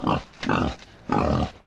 flesh_idle_4.ogg